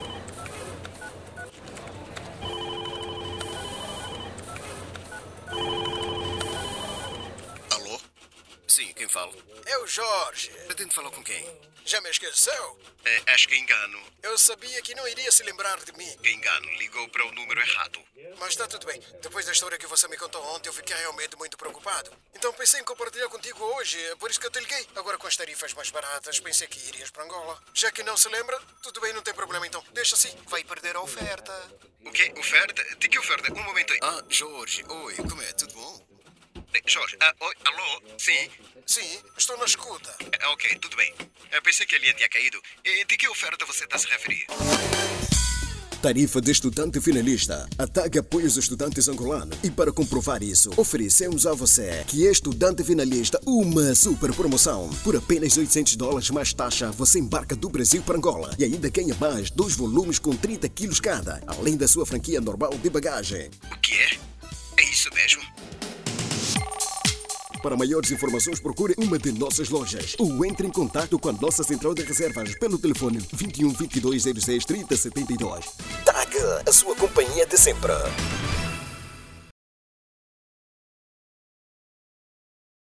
Masculino
Voz Padrão - Grave 01:22